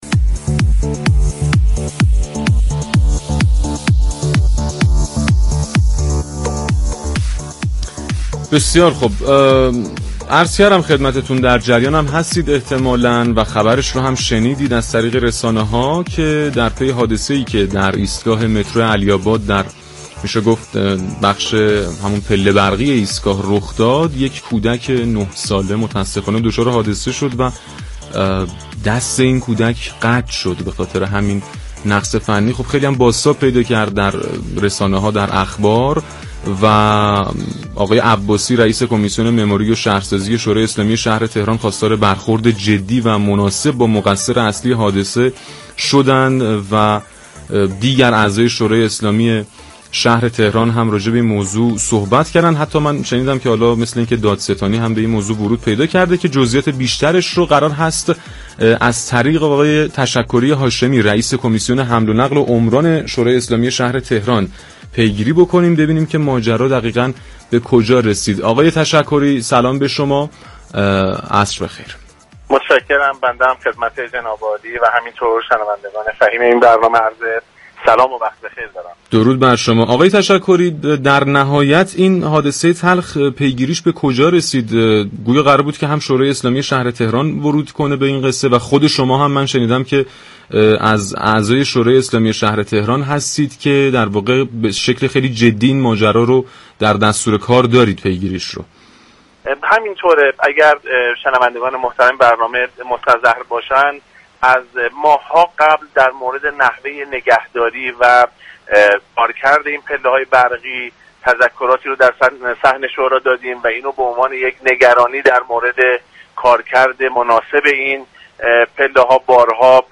در پی حادثه قطع دست یك دختر 9 ساله در یكی از پله برقی‌های شهر تهران واقع در خیابان تختی جنب متروی علی آباد؛ تشكری هاشمی رئیس كمسیون حمل و نقل و عمران شورای اسلامی شهر تهران در گفت و گو با رادیو تهران توضیحاتی ارائه كرد.